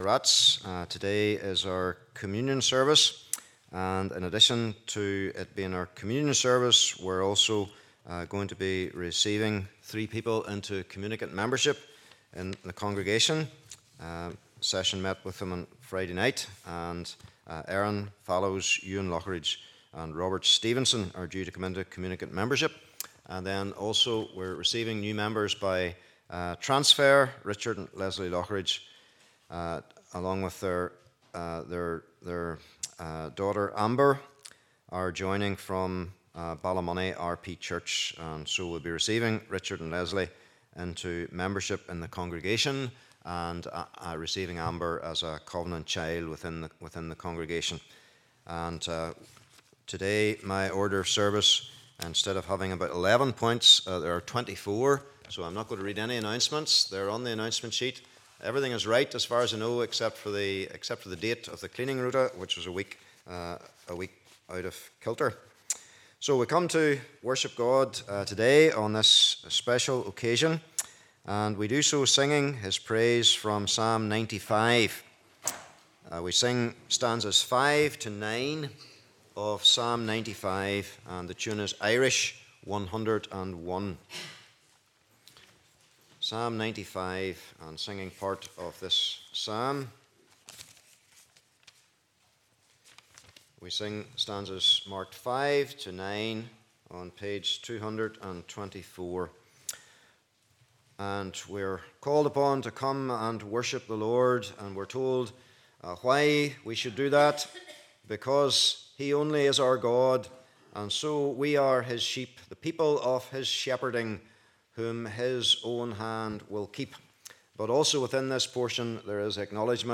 Communion Series May 2023 Passage: Isaiah ch53 v 6 Service Type: Morning Service « Living Stones and a Cornerstone The Suffering Servant